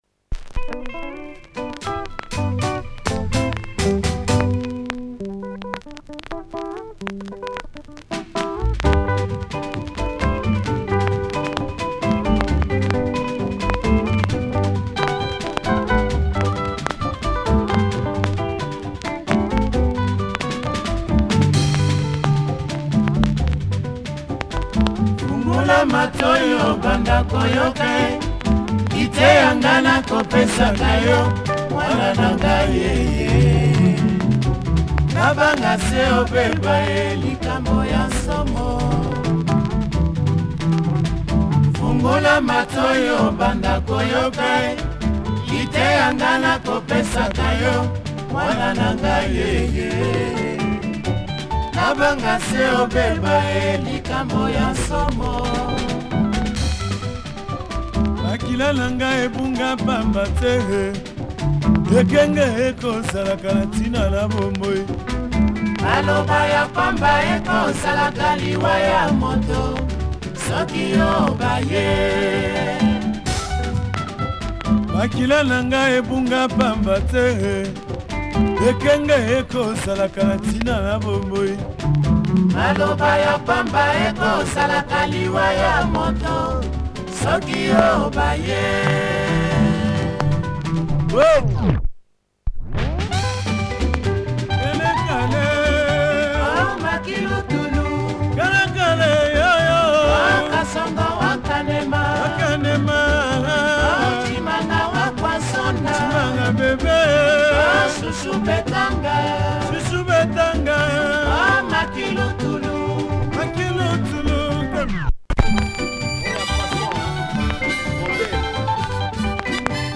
Nice accordian use on the second side, check audio! https